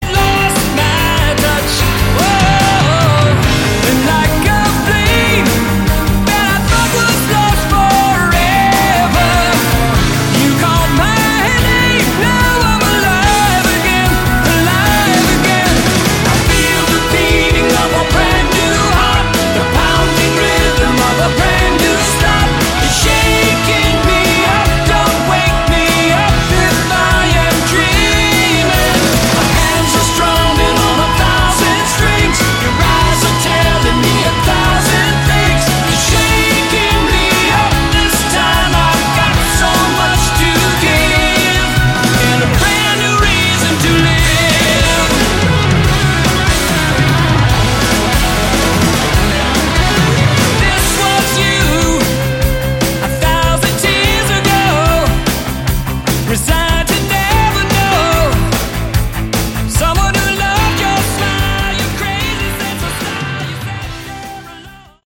Category: AOR
lead vocals
guitar, keyboards
bass
drums